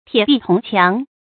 鐵壁銅墻 注音： ㄊㄧㄝ ˇ ㄅㄧˋ ㄊㄨㄙˊ ㄑㄧㄤˊ 讀音讀法： 意思解釋： 比喻十分堅固，不可摧毀的事物。